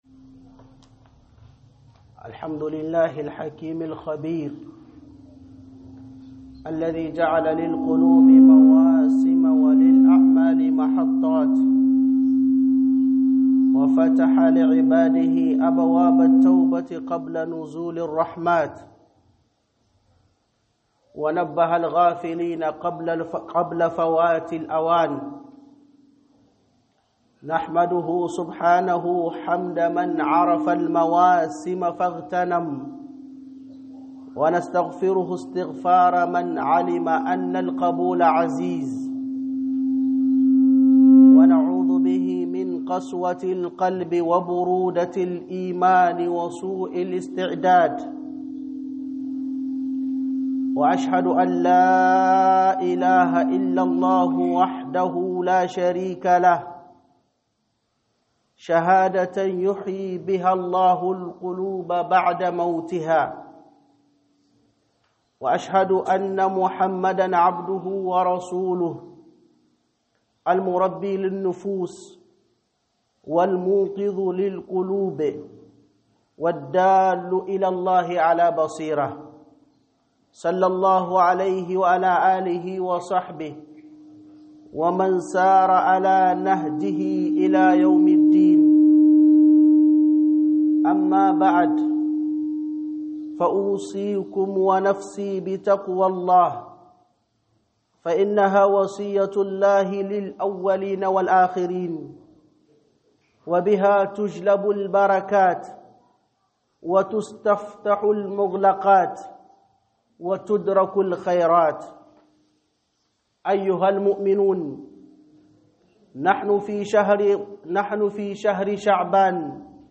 04_خطبة_الجمعة_١١_شعبان - HUƊUBAR JUMA'A